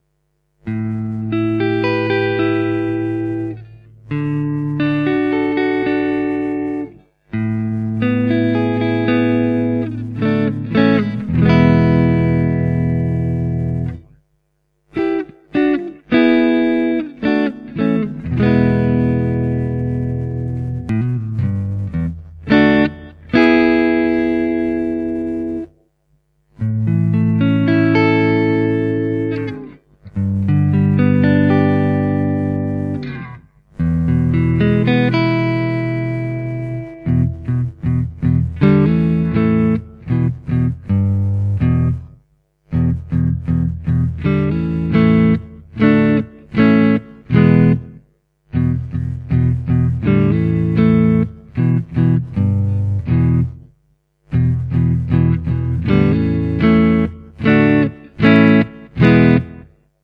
It has an aggressive, edgy sound that is slightly darker, ideal for blues and classic rock to old metal. The ACT tapped sound is is warmer, smoother and fatter than a conventional centre tap.
Bridge Full      Bridge Tapped        Neck Full